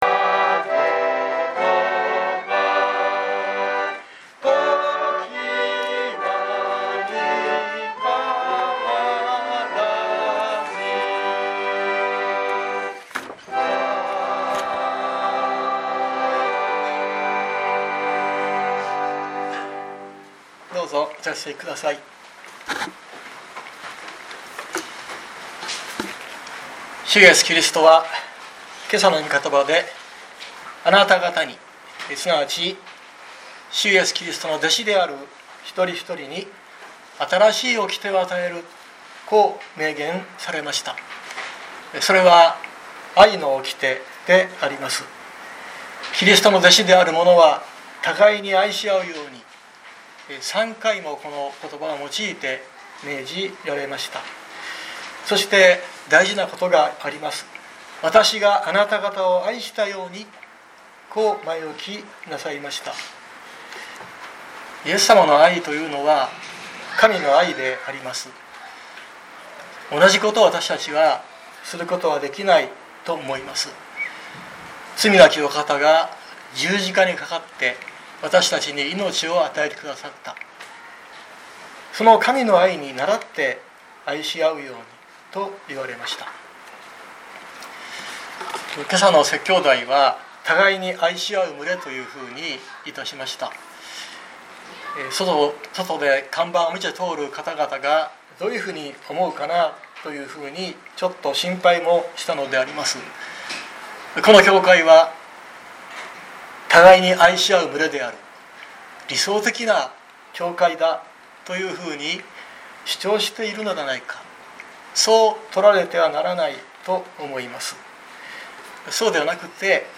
熊本教会。説教アーカイブ。
日曜朝の礼拝